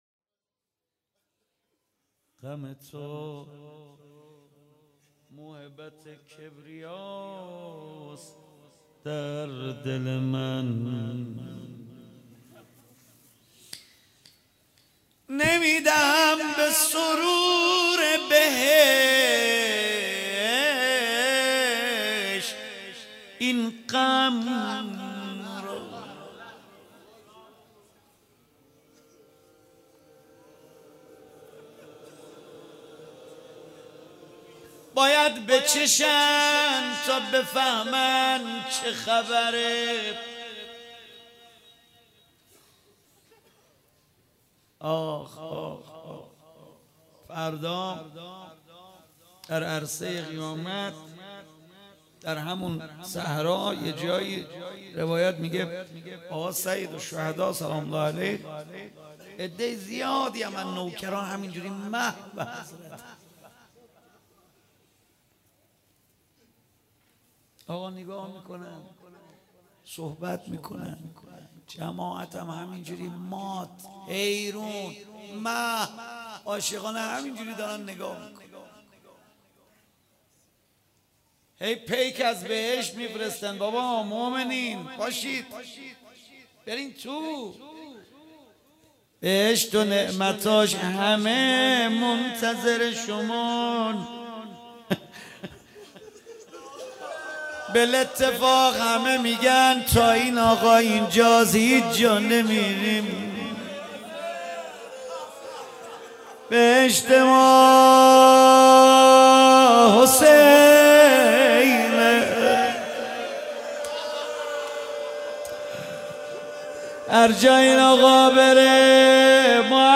شب عاشورا محرم 97 - روضه